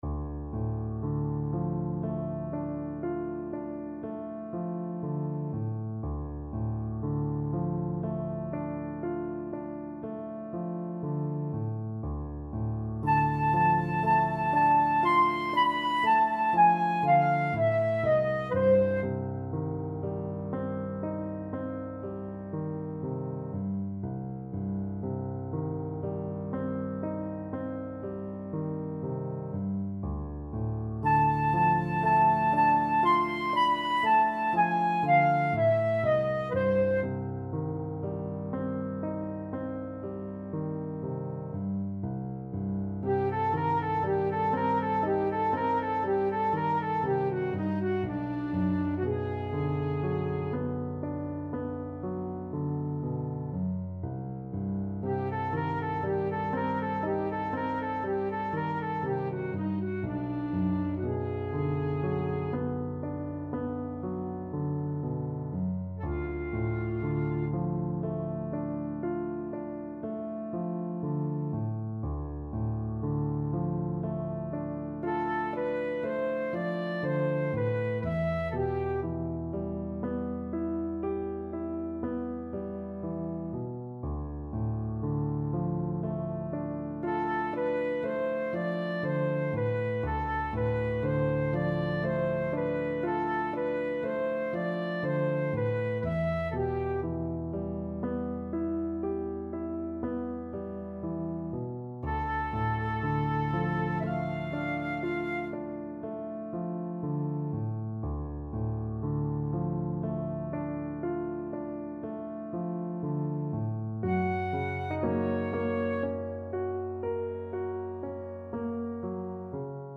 Flute
C major (Sounding Pitch) (View more C major Music for Flute )
Lento
Classical (View more Classical Flute Music)